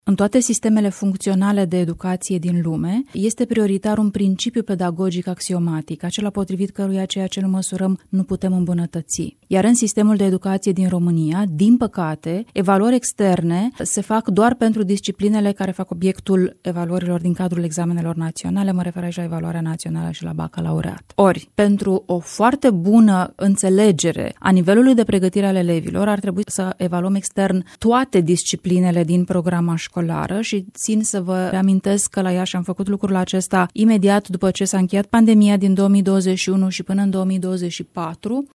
Reforma educației ar trebui să înceapă cu introducerea unui sistem extins de evaluări externe, a declarat în emisiunea, Dezbaterea Zilei, Luciana Antoci, consilier de stat în cancelaria prim-ministrului.